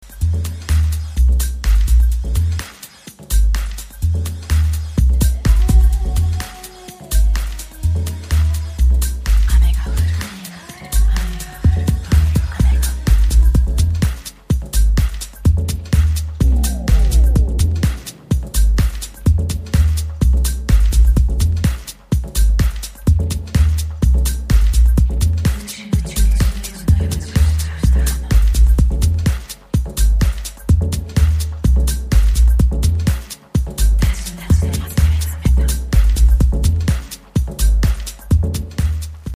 Seductive dubby deep house...